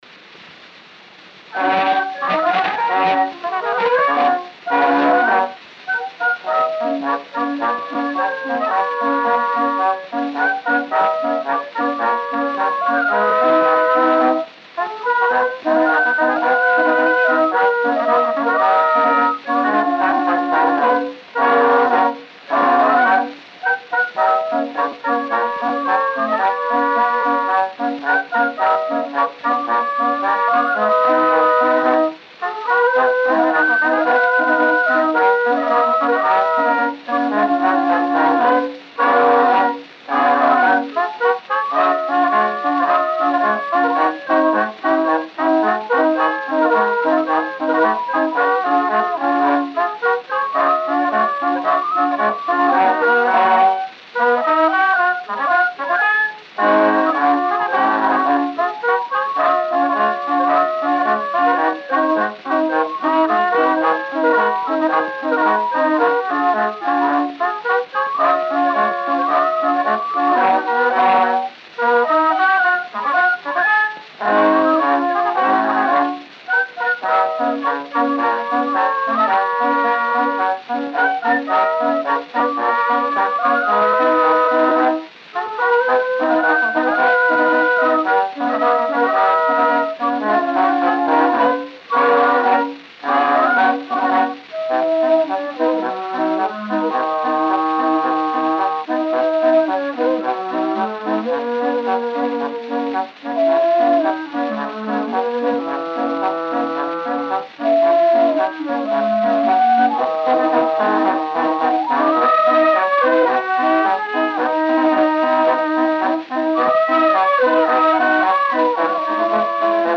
Disco de 78 rotações, também chamado "78 rpm", gravado nos dois lados e com rótulo "tricolor".